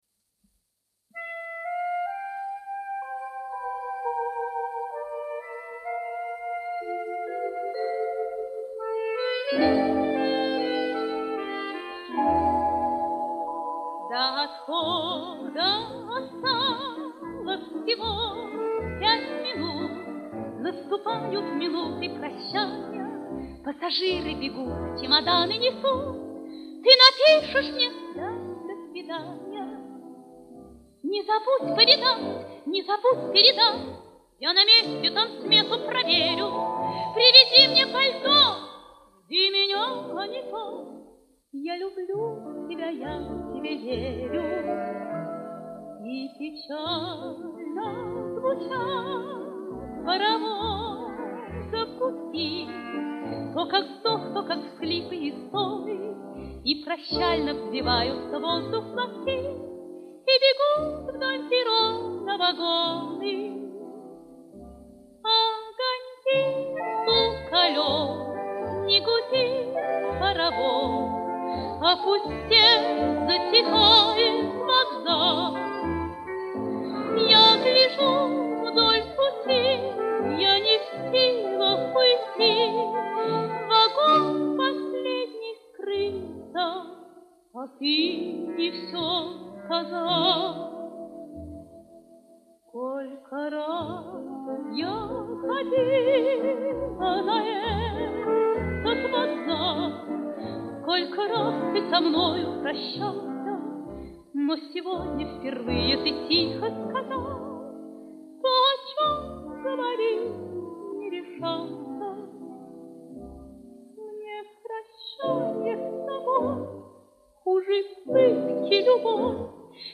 Оцифровка пластинки на 78 об.